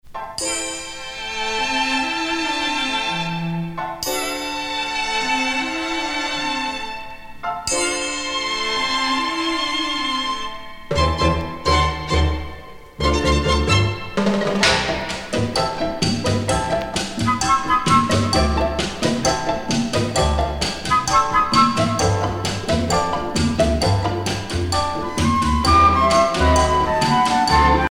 danse : cha cha cha